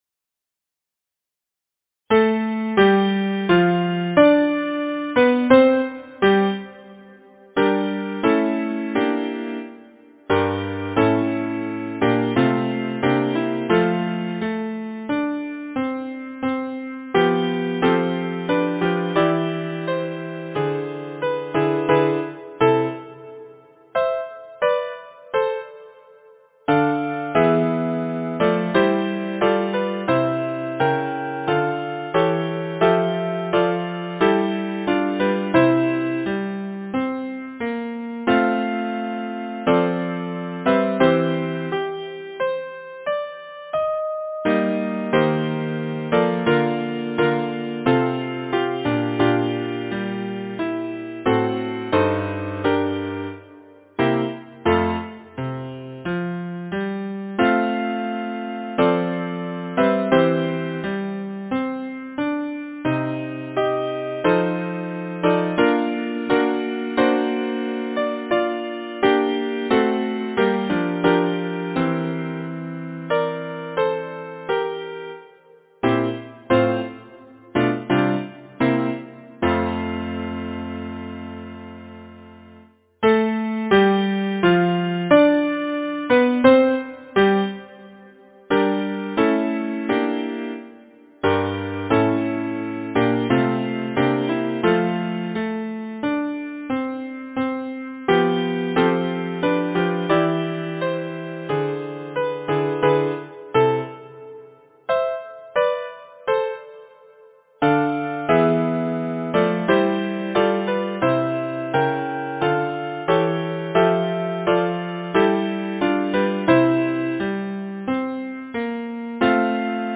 Number of voices: 4vv Voicing: SATB Genre: Secular, Partsong
Language: English Instruments: a cappella or Keyboard